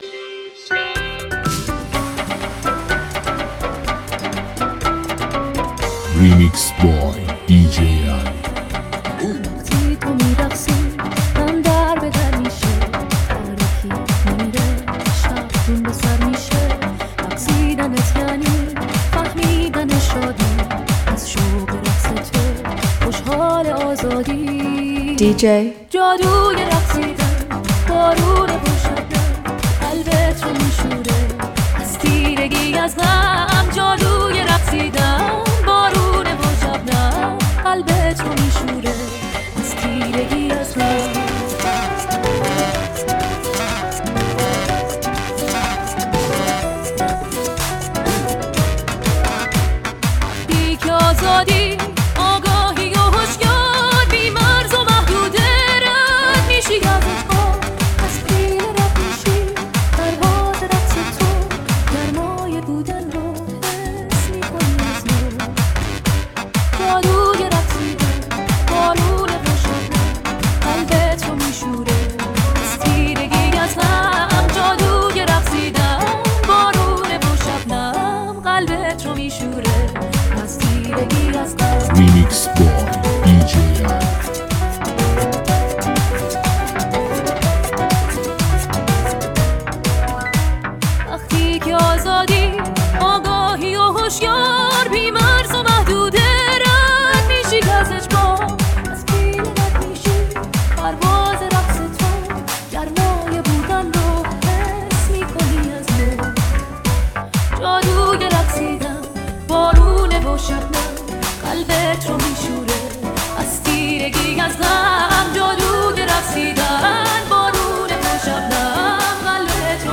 لذت بردن از موسیقی پرانرژی و بیس قوی، هم‌اکنون در سایت ما.
این نسخه با سبک رقص و بیس قوی